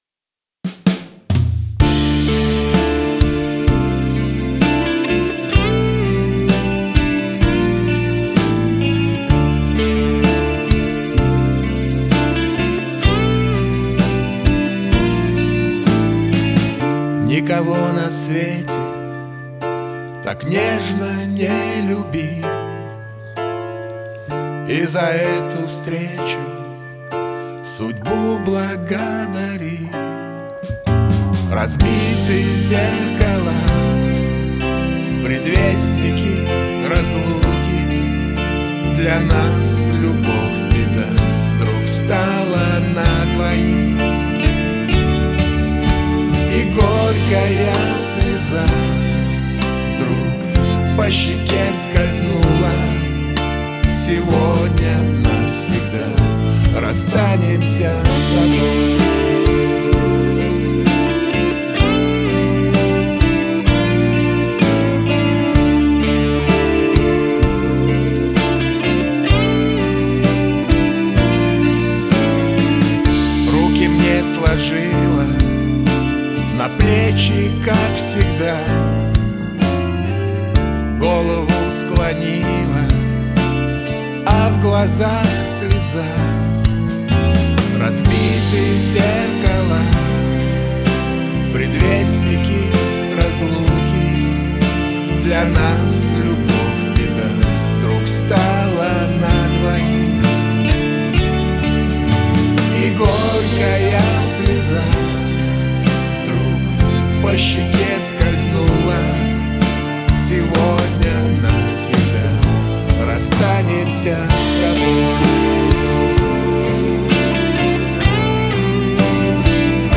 Поп Рок